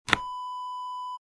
Small Drawer Close Wav Sound Effect #2
Description: The sound of a small wooden drawer being closed
Properties: 48.000 kHz 16-bit Stereo
A beep sound is embedded in the audio preview file but it is not present in the high resolution downloadable wav file.
Keywords: wooden, small, tiny, drawer, dresser, push, pushing, close, closing
drawer-small-close-preview-2.mp3